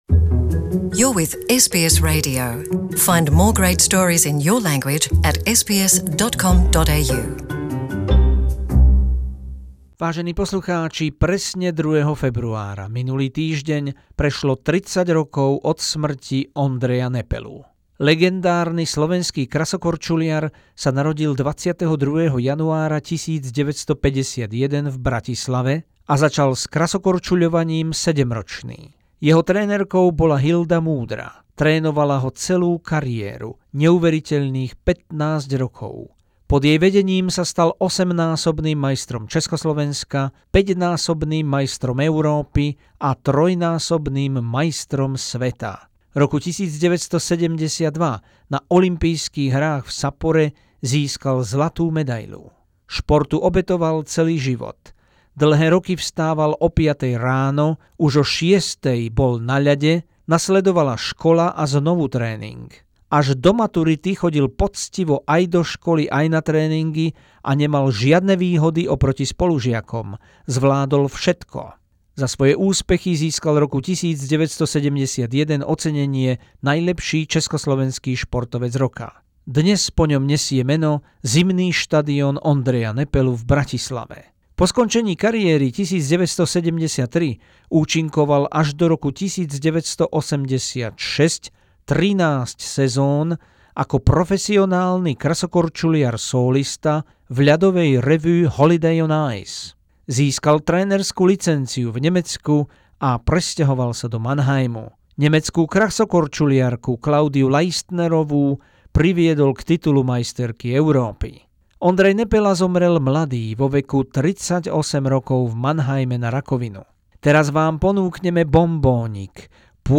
Unique, precious and priceless report from the original live coverage of 1972 Winter Olympics in Sapporo, Japan.